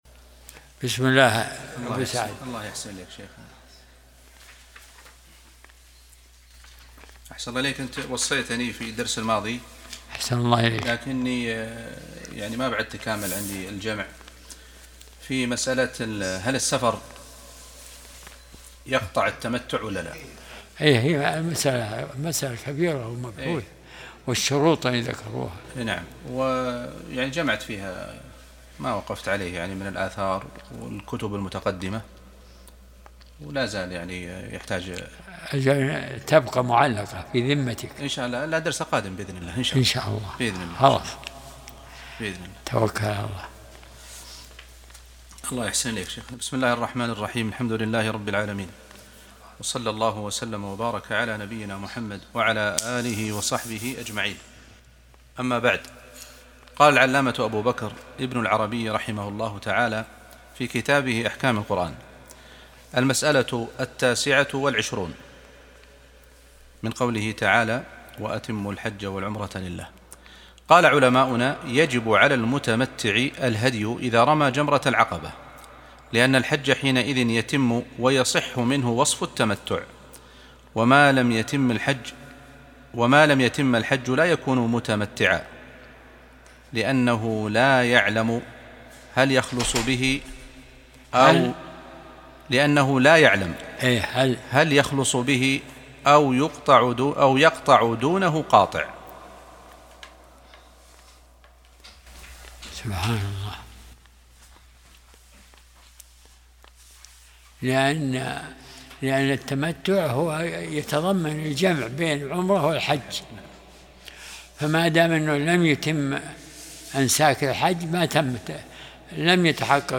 درس الأحد 75